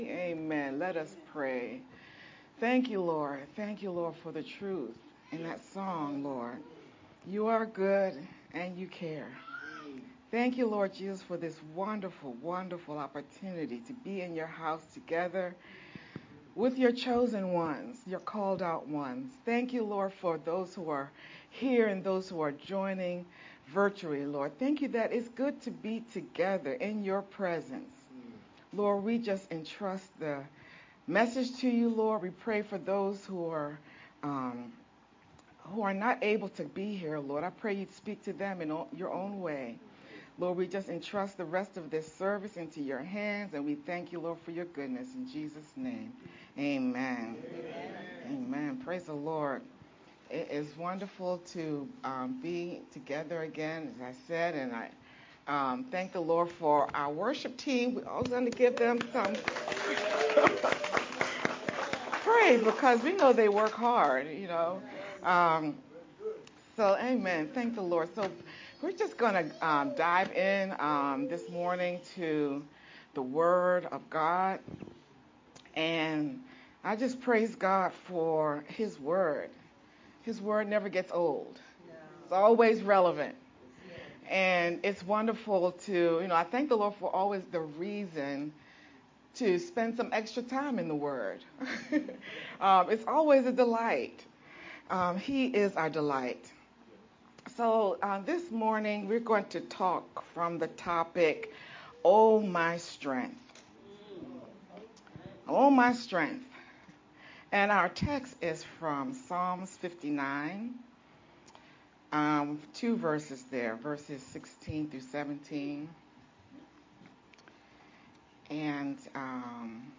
VBCC-edited-sermon-only-8-10_Converted-CD.mp3